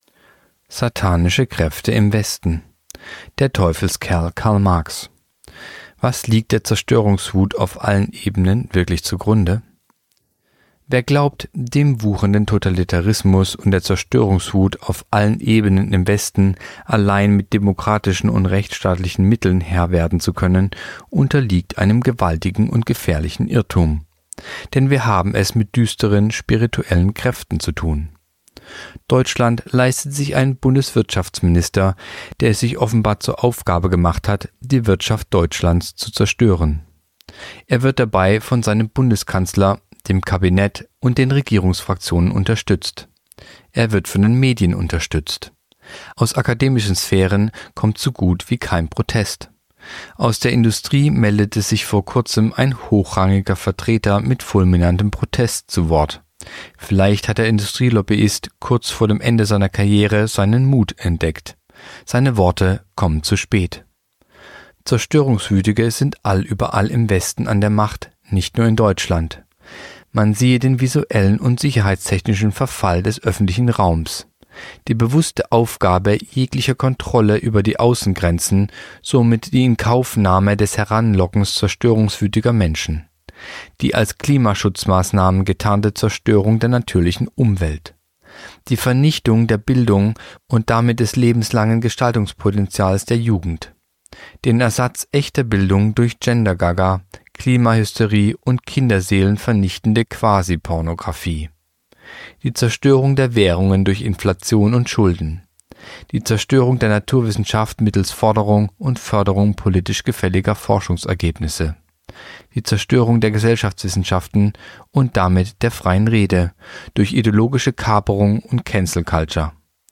Artikel der Woche (Radio)